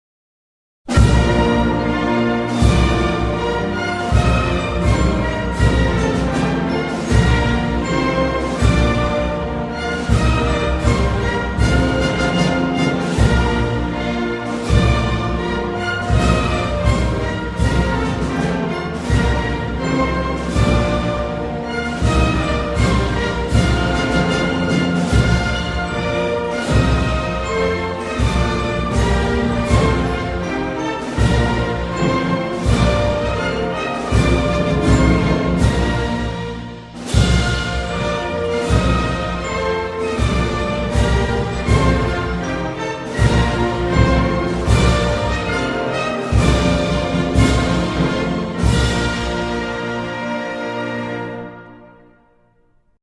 Spanish_anthem.mp3